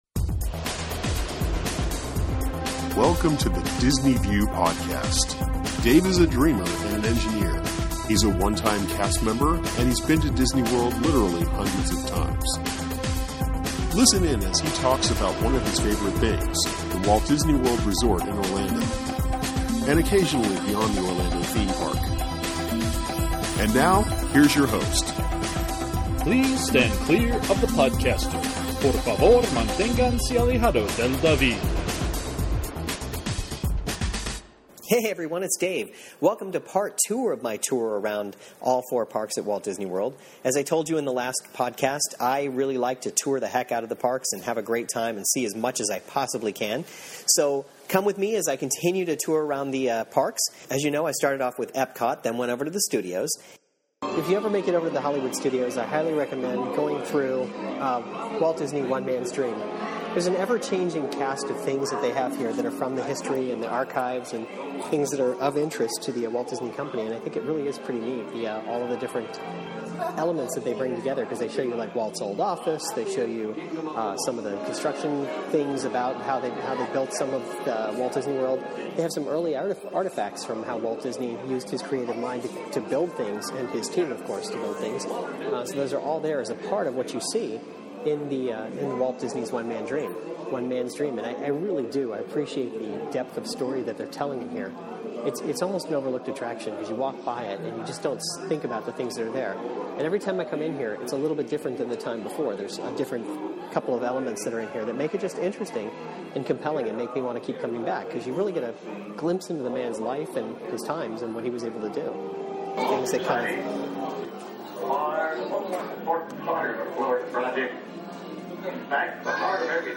Back in May, I took a trip to the parks, and started a 4-park adventure in a single day. On this podcast, you'll hear me head over to the Studios, then head for a quick nap - and then its off to the Animal Kingdom